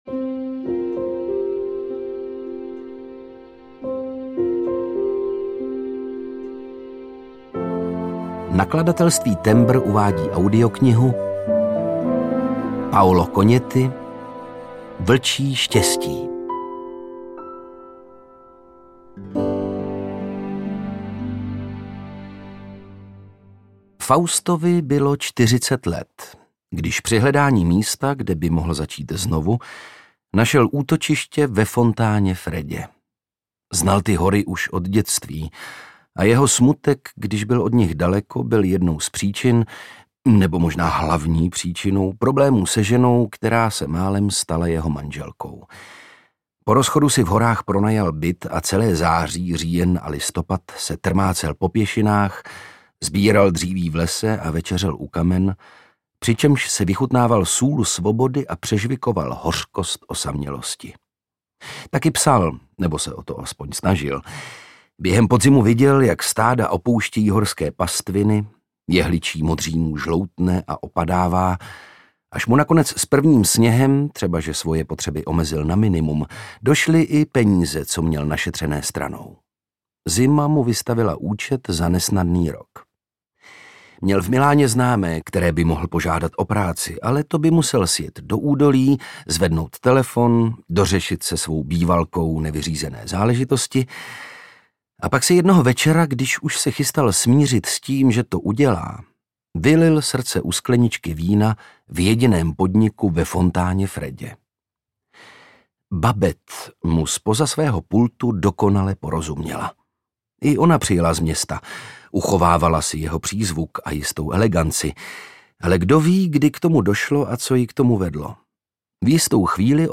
Vlčí štěstí audiokniha
Ukázka z knihy
• InterpretOndřej Brousek